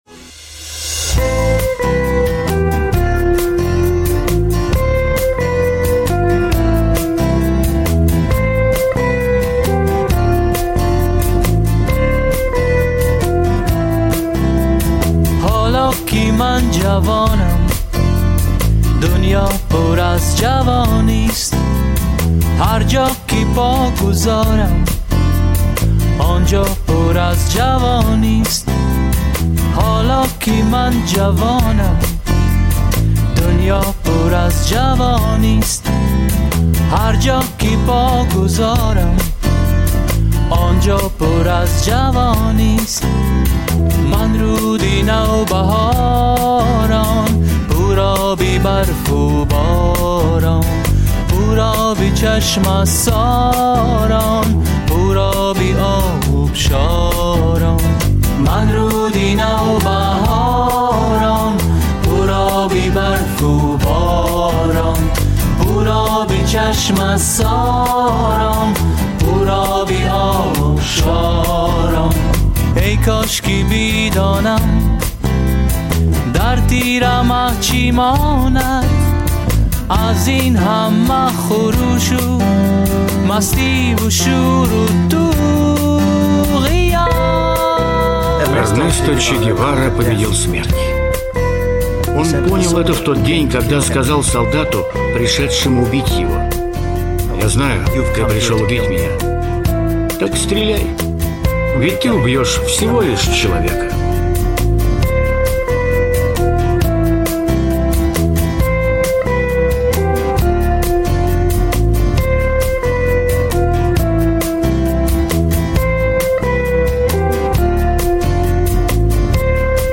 Мусиқа ва тарона